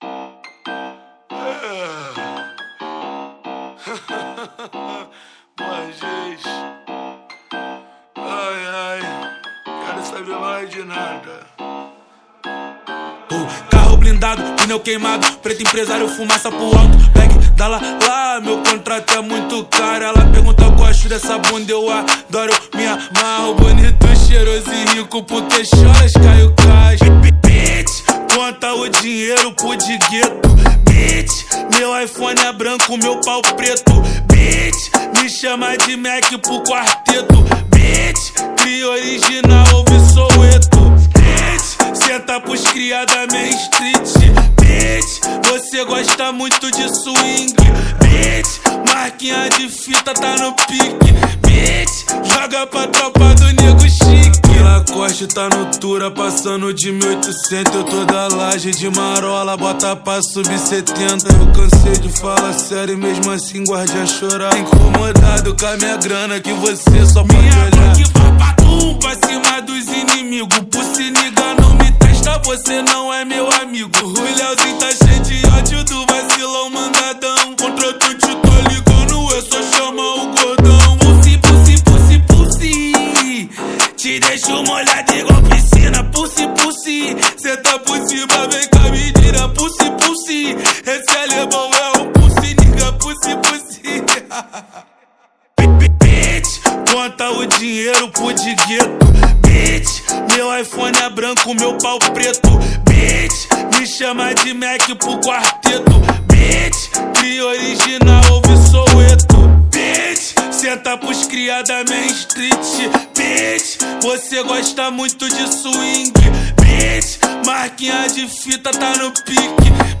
2025-02-14 20:48:45 Gênero: Funk Views